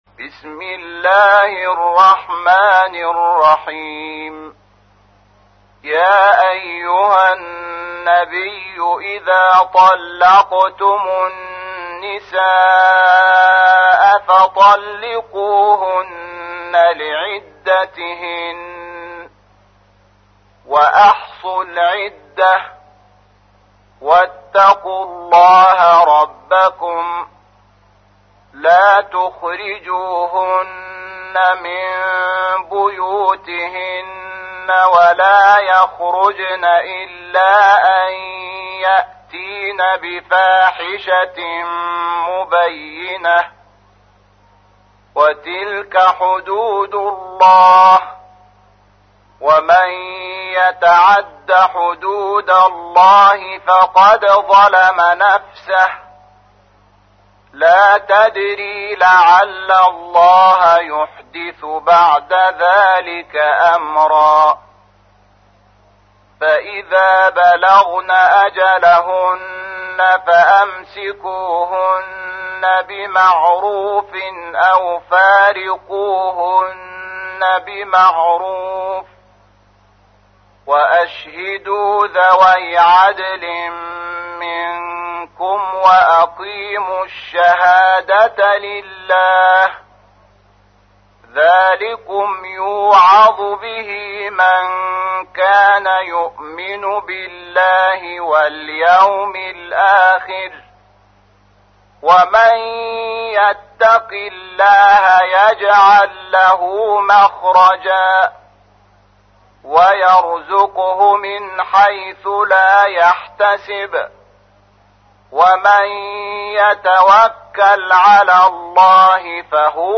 تحميل : 65. سورة الطلاق / القارئ شحات محمد انور / القرآن الكريم / موقع يا حسين